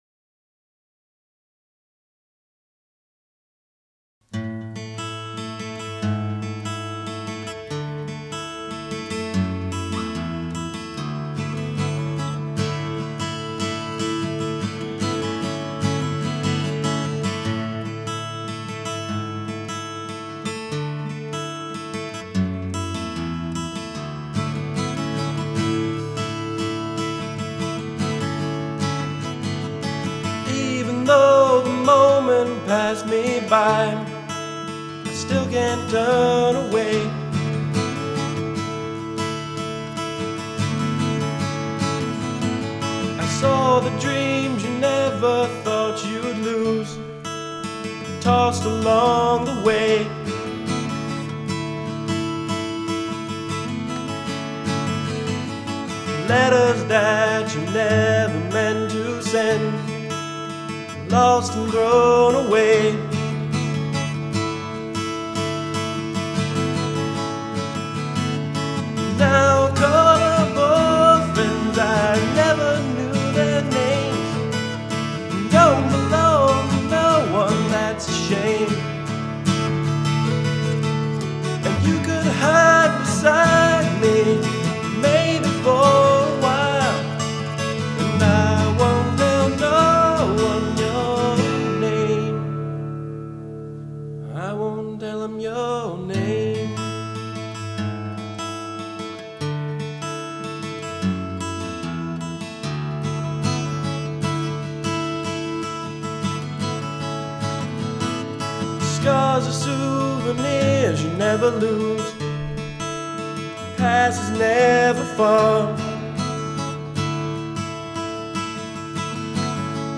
(acoustic)